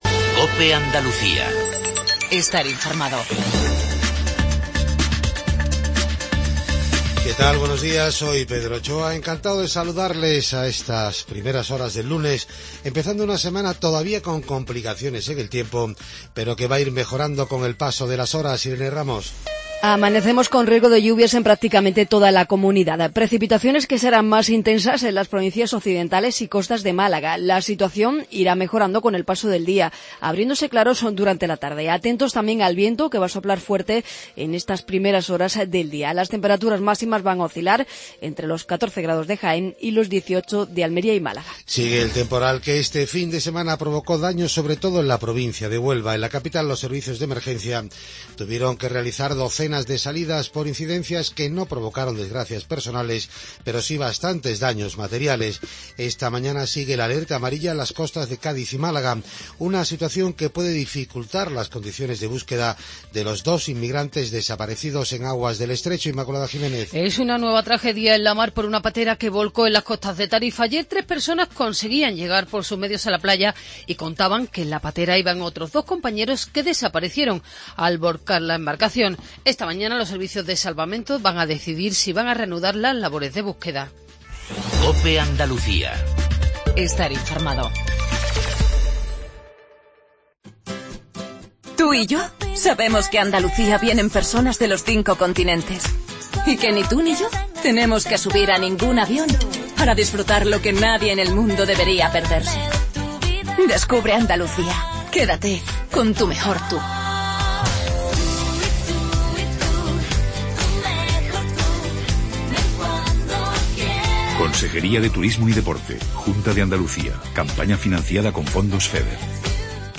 INFORMATIVO REGIONAL MATINAL 7:20